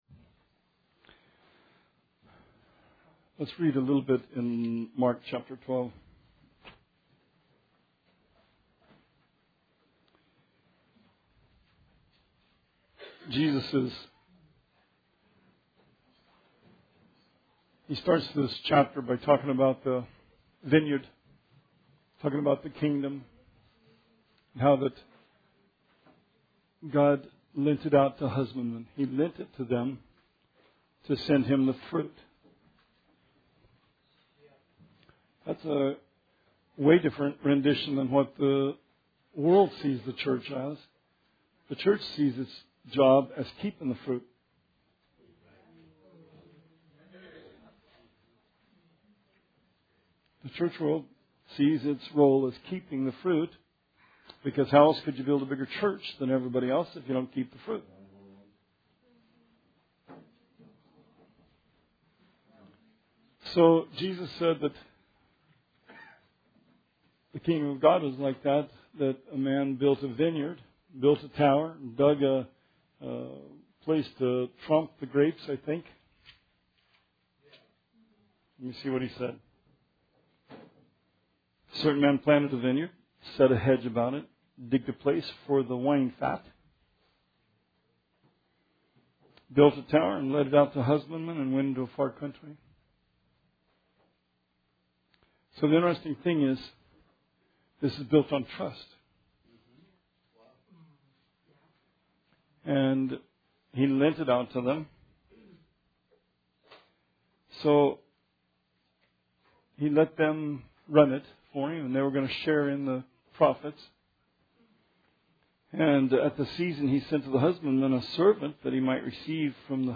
Bible Study 5/3/17